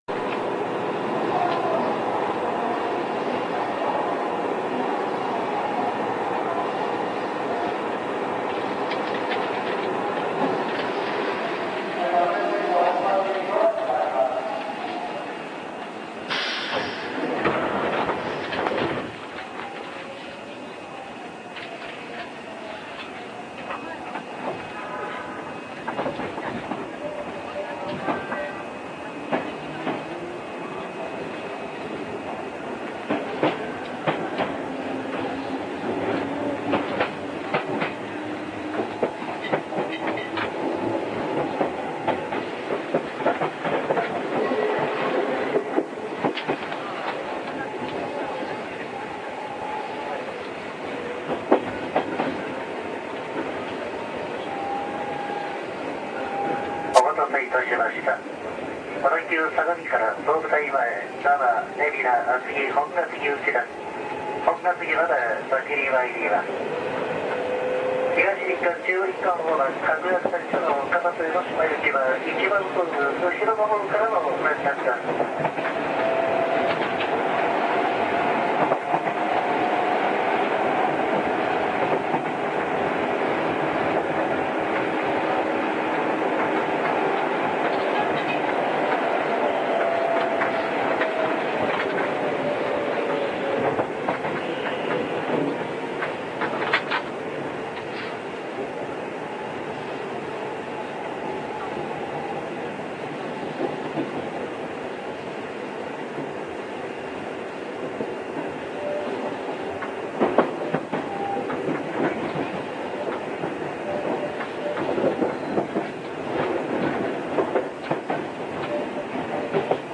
若干音が違います。また、今では少なくなった非ロングレール区間なのでローカルムード満点です。
小田急線　新松田〜渋沢（9104）